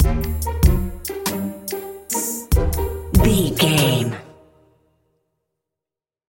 Ionian/Major
orchestra
strings
percussion
flute
silly
circus
goofy
comical
cheerful
perky
Light hearted
quirky